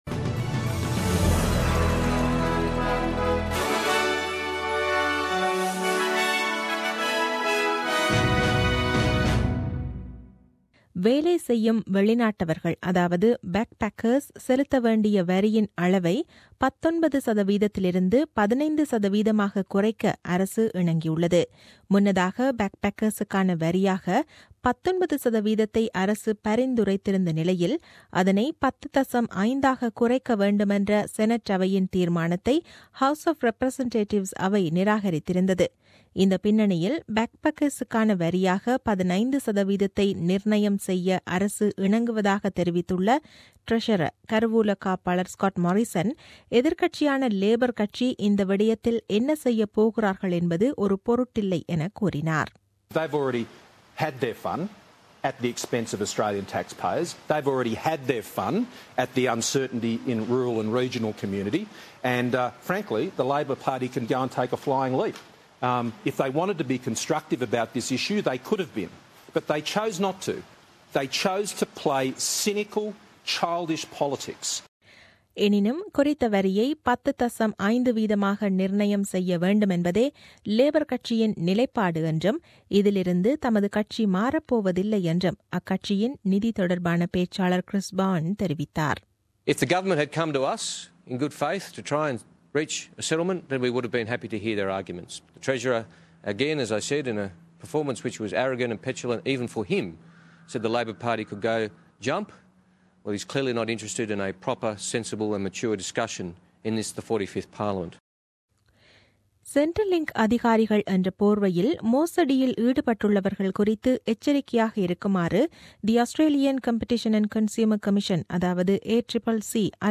The news bulletin aired on 28 Nov 2016 at 8pm.